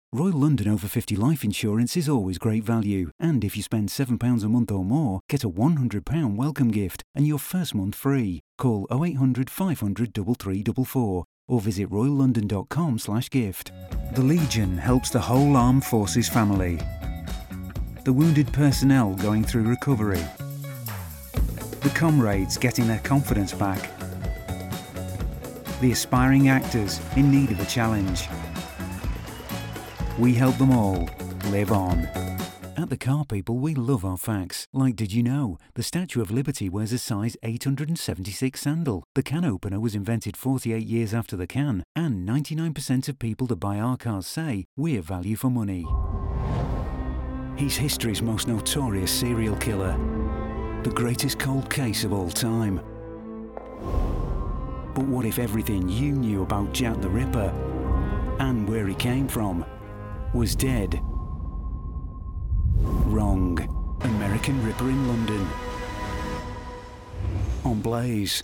• Male
• Northern